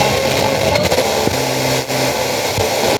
80BPM RAD0-R.wav